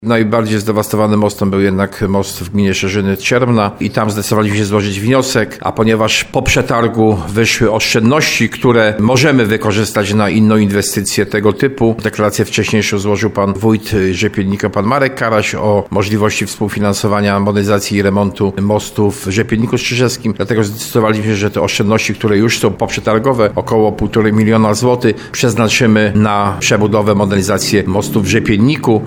Jak tłumaczy starosta tarnowski Jacek Hudyma, oszczędności są wystarczająco duże, by wyremontować także most w Rzepienniku Strzyżewskim.